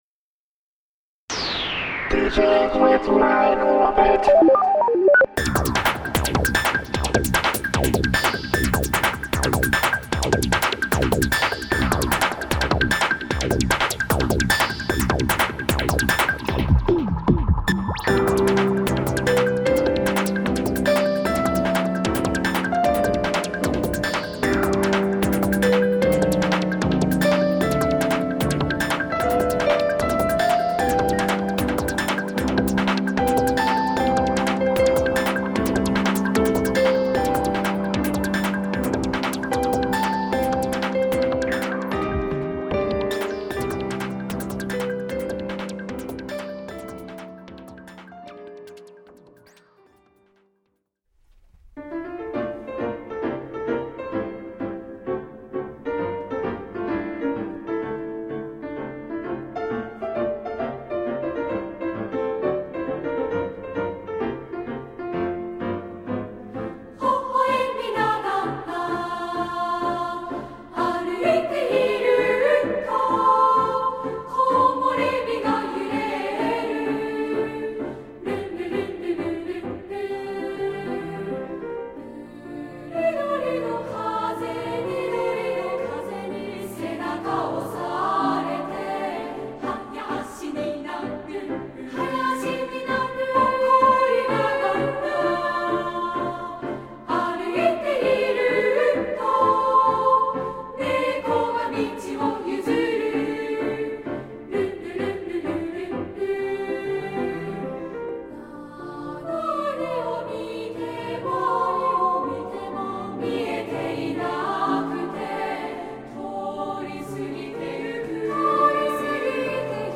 for Same Voice Chorus and Piano